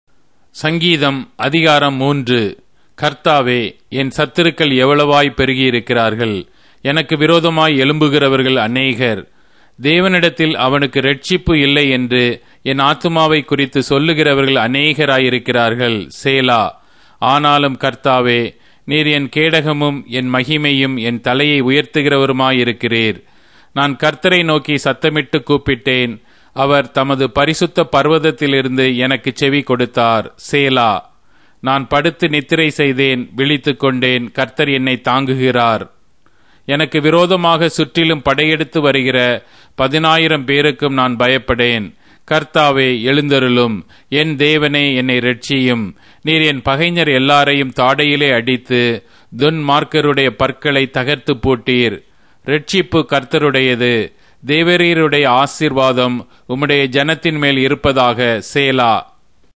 Tamil Audio Bible - Psalms 46 in Irvhi bible version